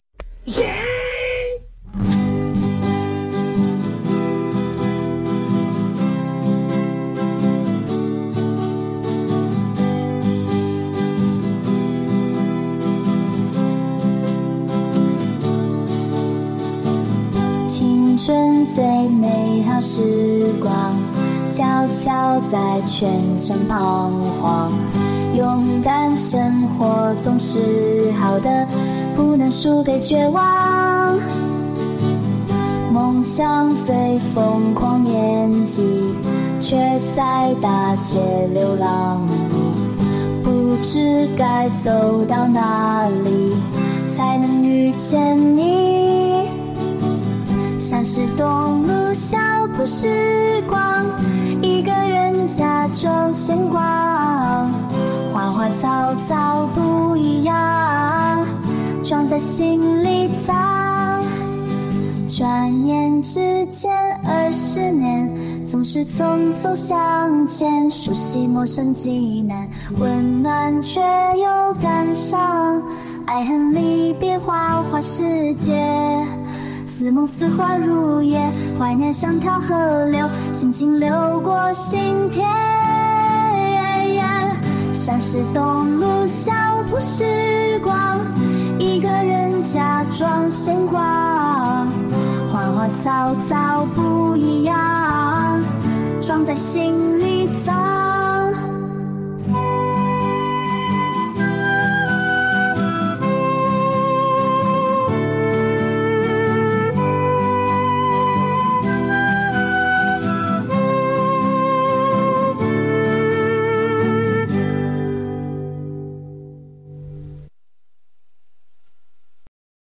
第一个版本是用平台提供的歌手音色生成，第二个版本是上传了音频，进行了自定义音色，两个感觉非常不一样，这就是Mureka个性化音色的魅力。
泉城之恋-上传人声音频版本.mp3